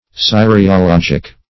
Search Result for " cyriologic" : The Collaborative International Dictionary of English v.0.48: Cyriologic \Cyr`i*o*log"ic\ (s?r`?-?-l?j"?k or s?`r?-), a. [See Curiologic .] Relating to capital letters.
cyriologic.mp3